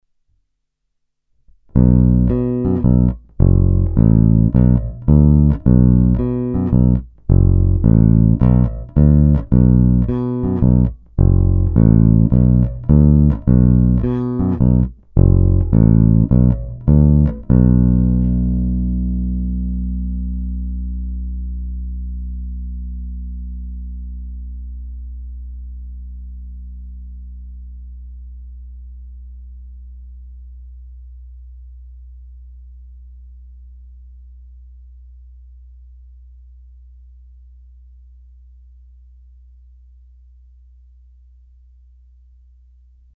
Brutálně konkrétní masívní zvuk.
Přitom všechny struny jsou vůči sobě perfektně vyvážené.
Není-li uvedeno jinak, následující nahrávky jsou vyvedeny rovnou do zvukové karty a s plně otevřenou tónovou clonou, následně jsou jen normalizovány, jinak ponechány bez úprav.